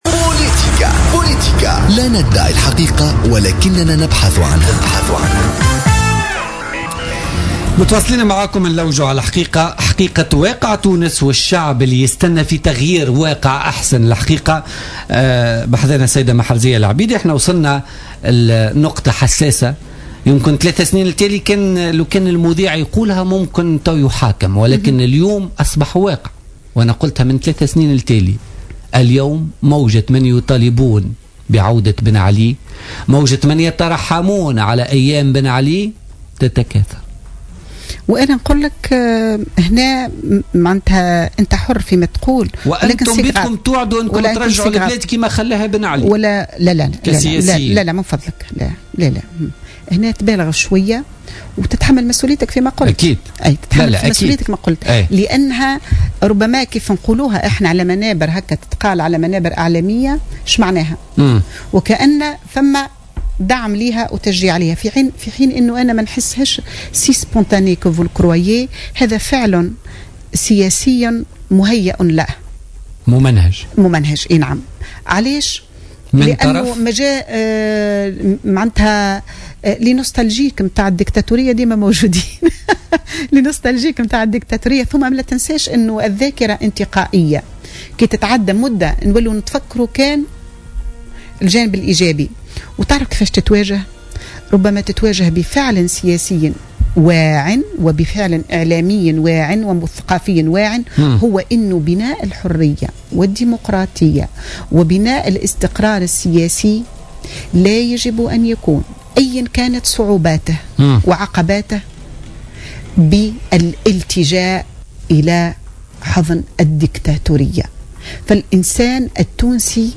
ردت النائب بمجلس نواب الشعب والقيادية في حركة النهضة محرزية العبيدي ضيفة برنامج بوليتكا لليوم الأربعاء 14 أكتوبر 2015 على موجة من يطالبون بعودة بن علي ويترحمون عليه.